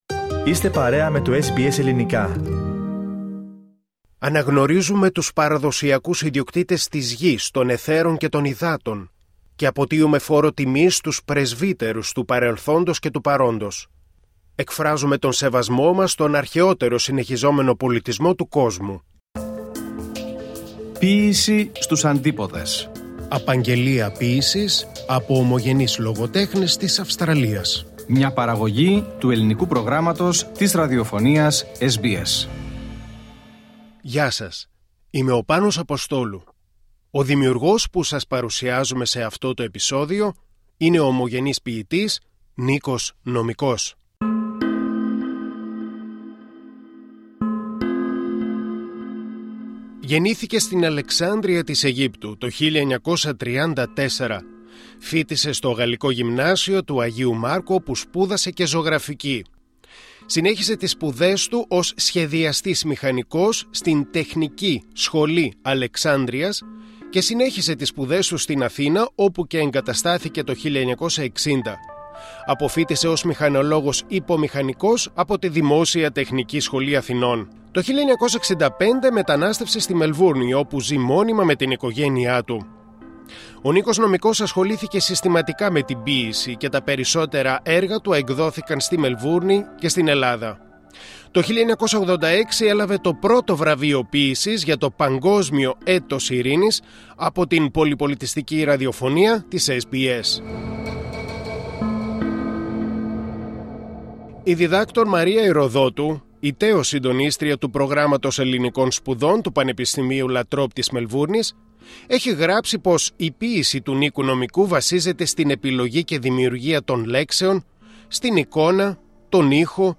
απαγγέλλει ποιήματά του και μιλά για τον συμβολισμό της ποίησής του και για τα παιδικά του χρόνια σε Αλεξάνδρεια, Χίο και Σύρο